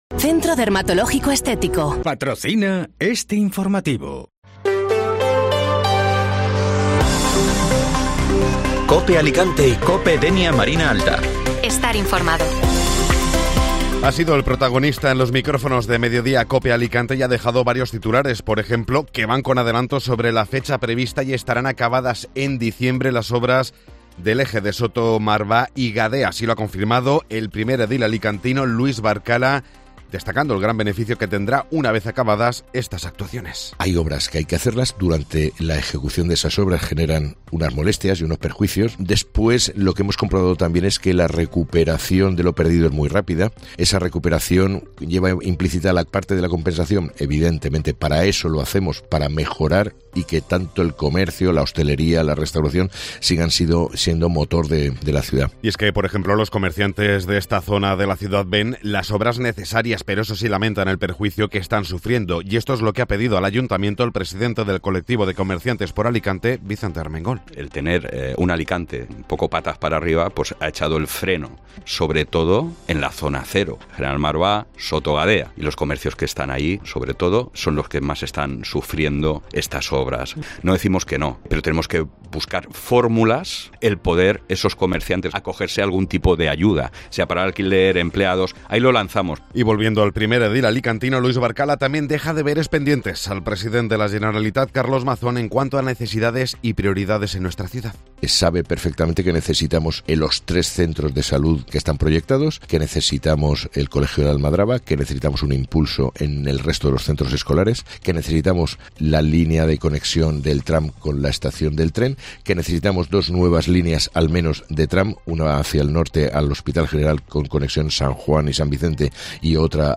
Informativo Matinal (Jueves 7 de Septiembre)